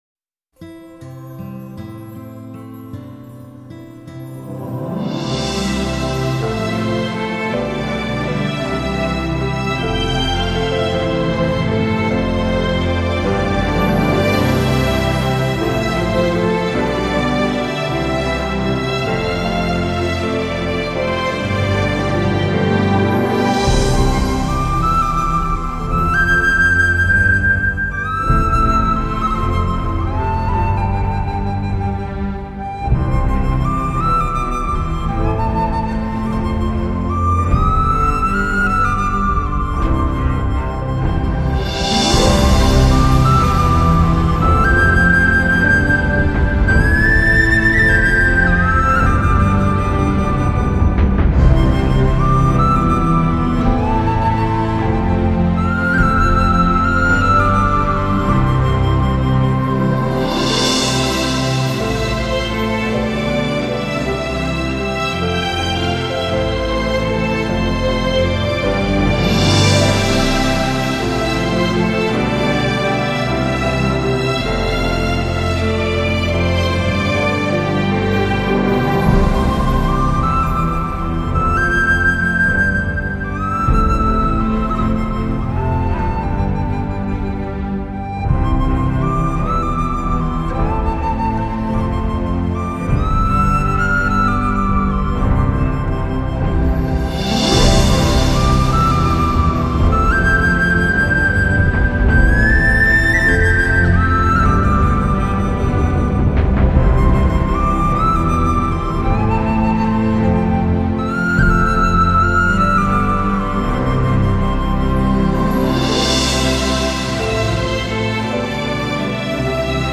优美的、宁静的、动人心弦的音乐。